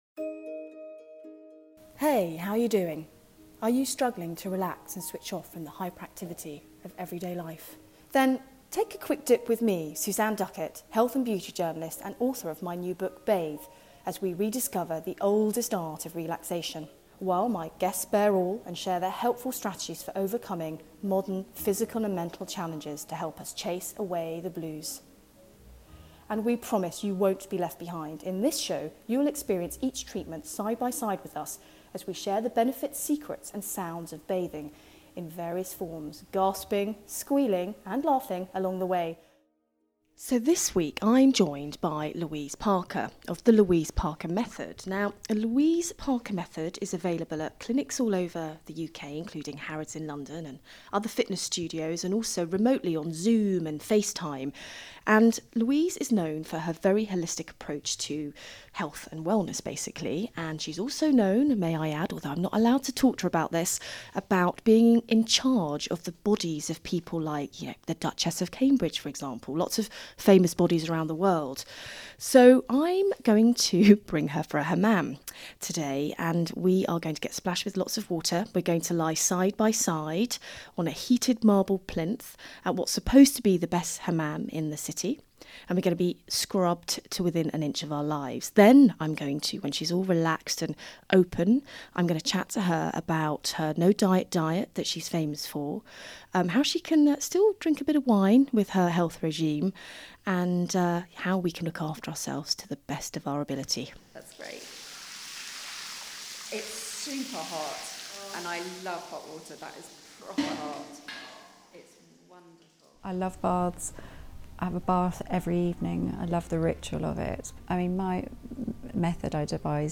And you won’t be left behind – in this podcast, listeners get up close and personal with the experience: the benefits, secrets and sounds of each treatment.
Recorded on location in the Hammam at Four Season Trinity Square, London.